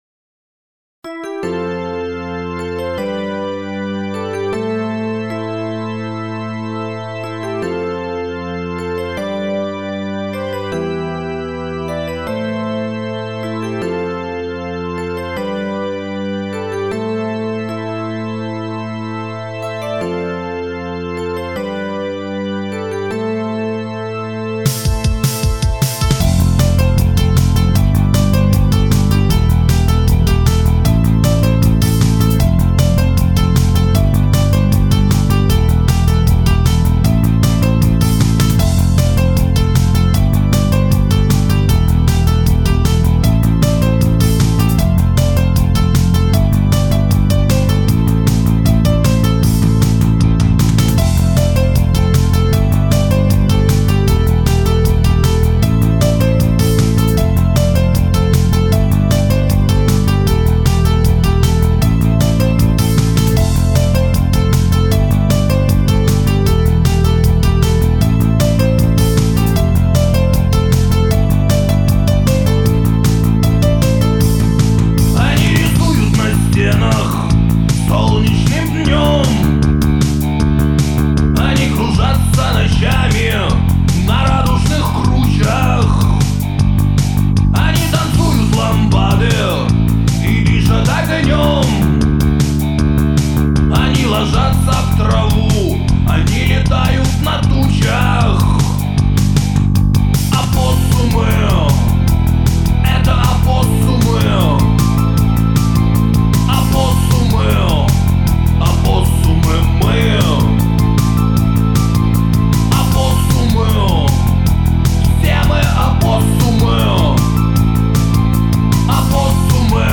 Стерео stereo